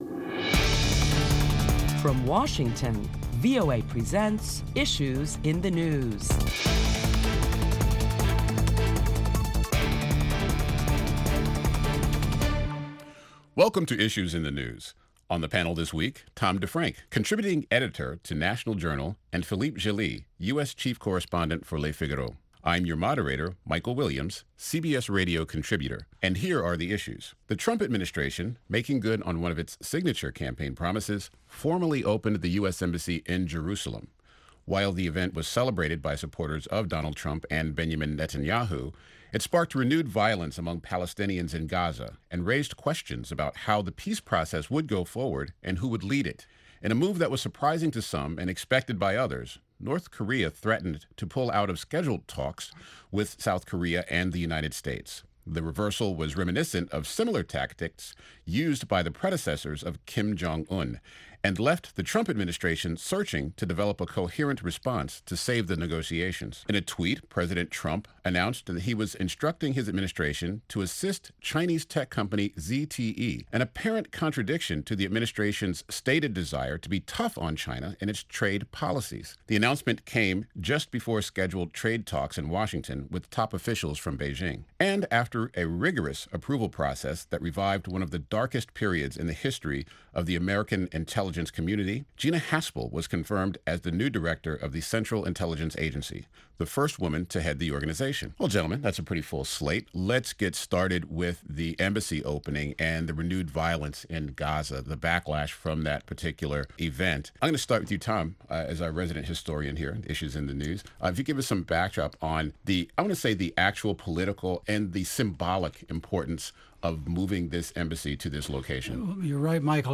Issues in the News, moderator